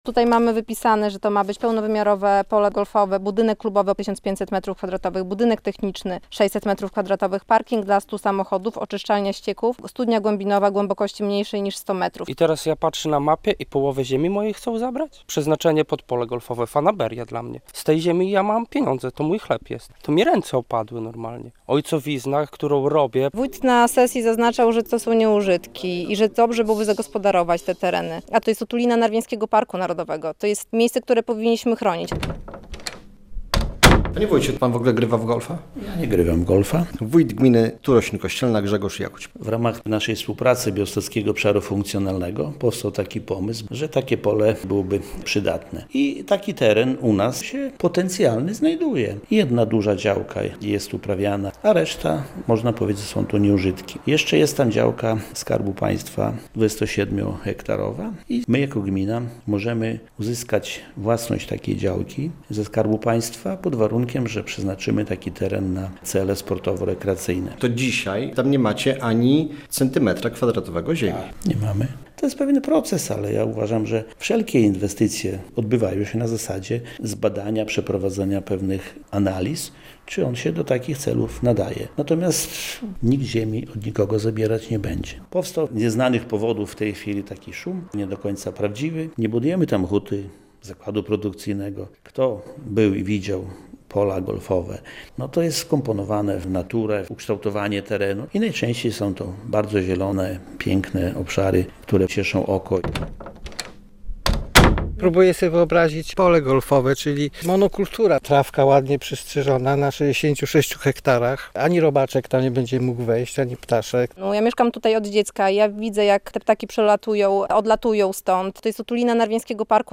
Wiadomości - W otulinie Narwiańskiego Parku Narodowego ma powstać pole golfowe - sprzeciwiają się temu mieszkańcy wsi Topilec